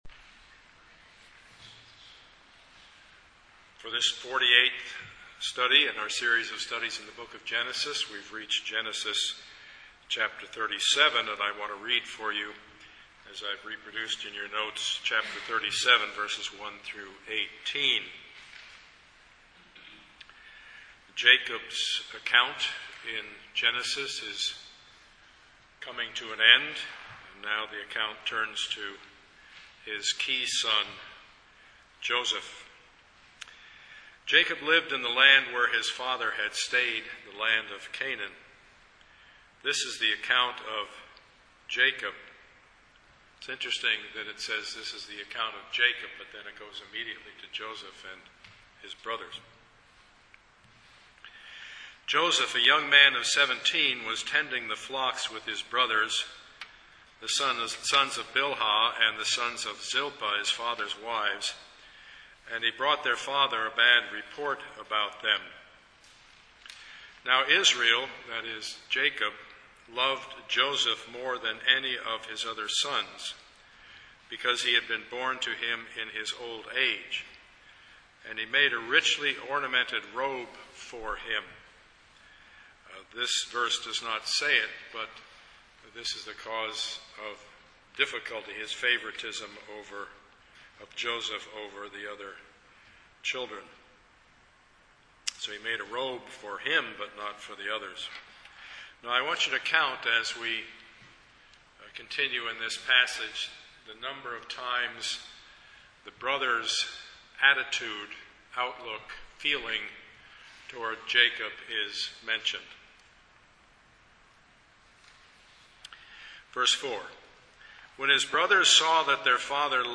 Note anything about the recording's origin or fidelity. Passage: Genesis 37:1-18 Service Type: Sunday morning